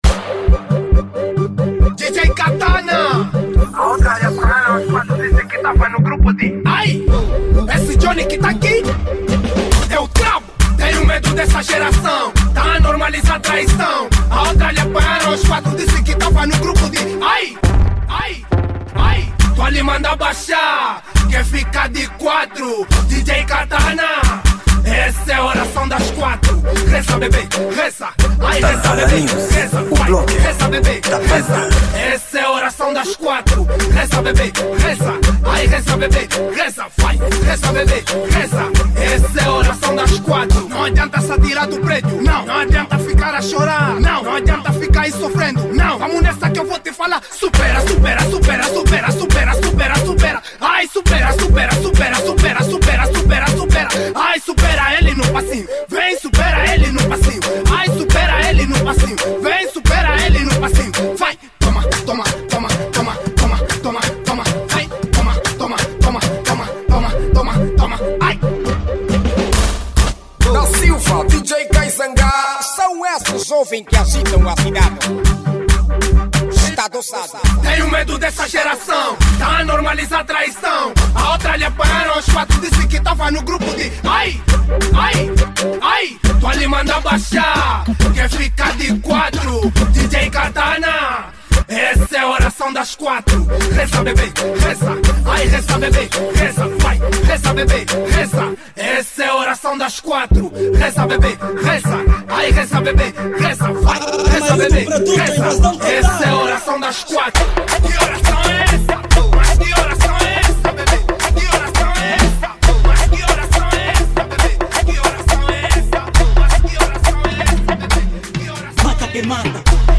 Gênero : Afro house